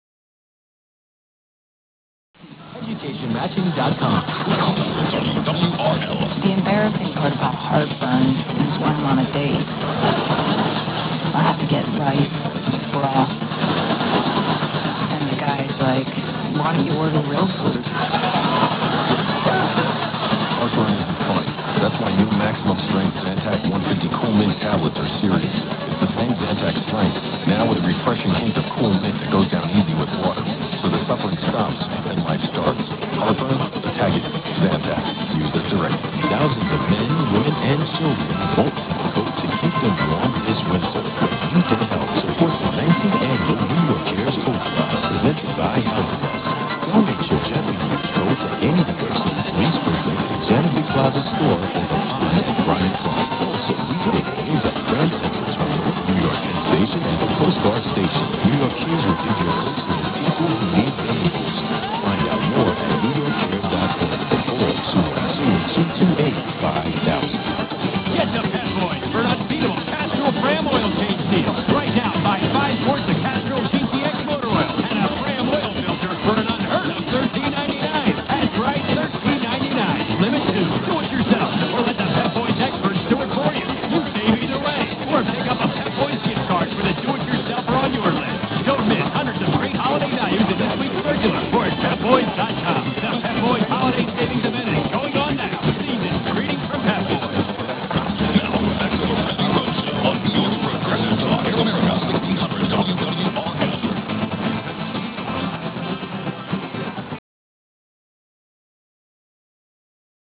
2007 DX Clips